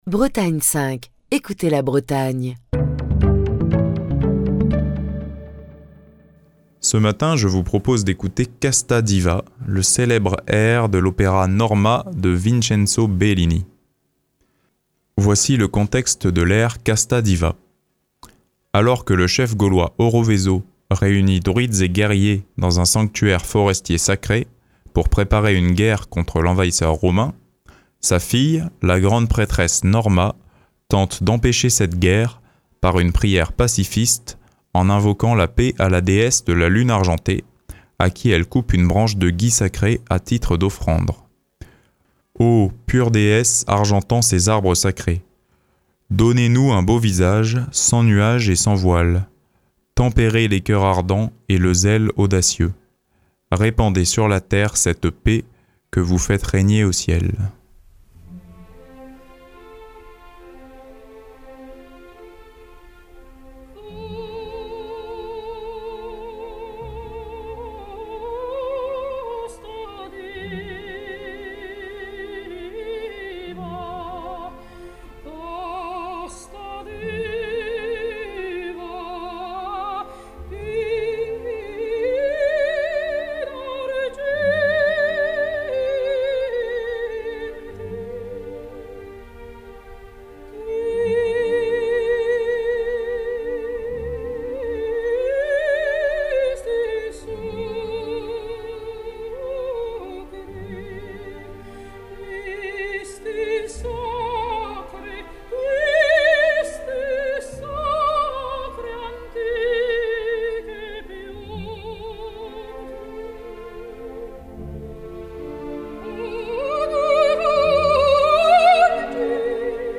Si un air peut résumer à lui seul ce qu'est l'opéra italien bel canto, c'est bien "Casta Diva", surtout lorsqu'il est interprété par Maria Callas à la Scala de Milan.